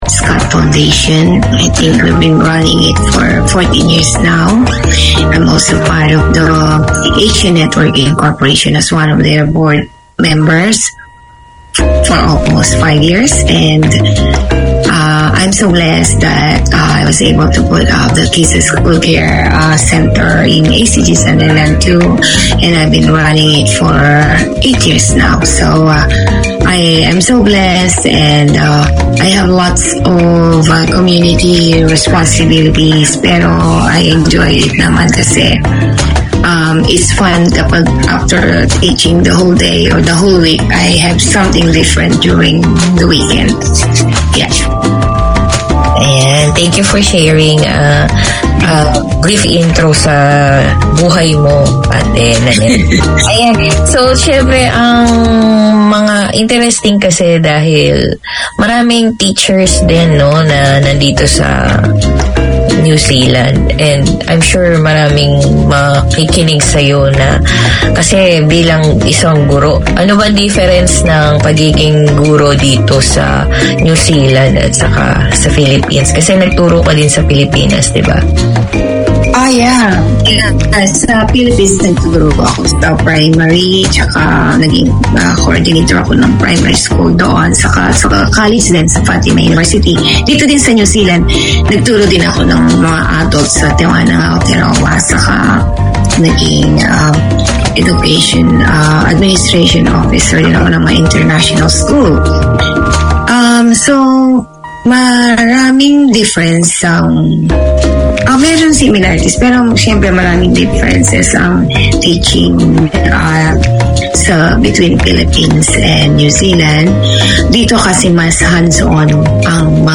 Community Access Radio in your language - available for download five minutes after broadcast.
The Filipino Show No shows scheduled this week Community magazine Language